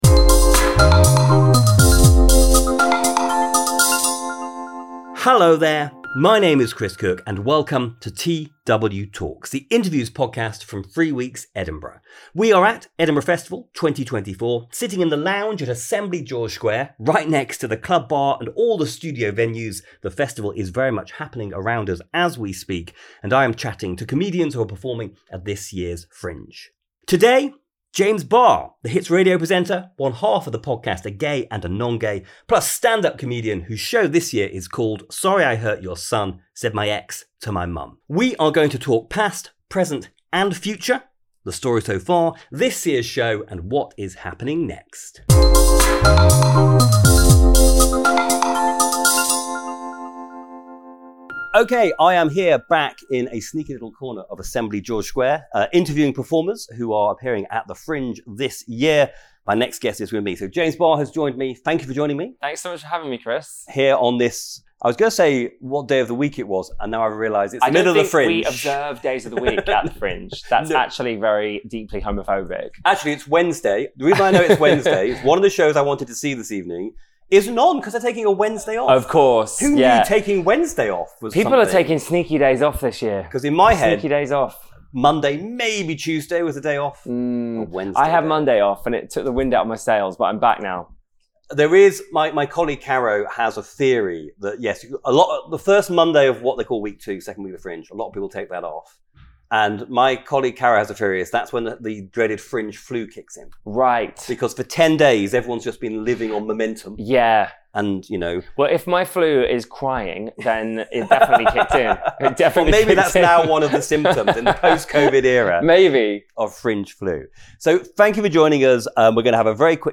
TW:Talks chats to people performing at the Edinburgh Festival 2024